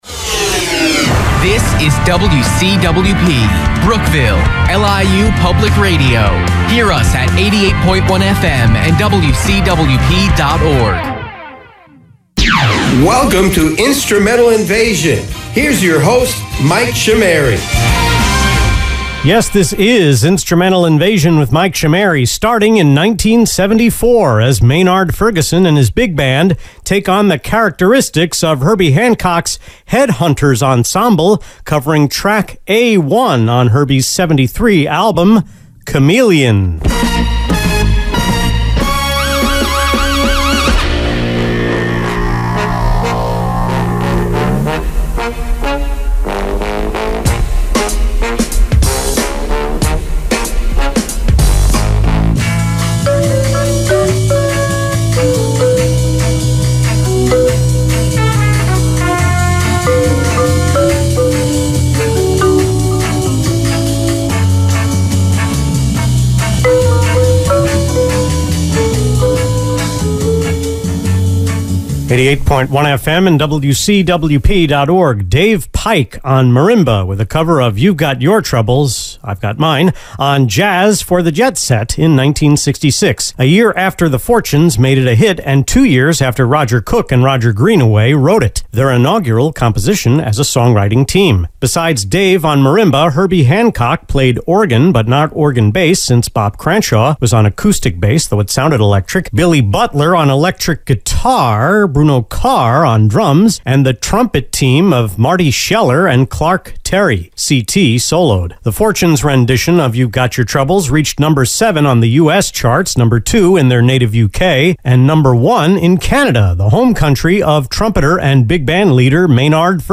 The airchecks were mostly recorded off the FM internet stream at my Wantagh home via desktop PCs in my bedroom and the guest room.